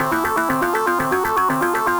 Index of /musicradar/8-bit-bonanza-samples/FM Arp Loops
CS_FMArp A_120-C.wav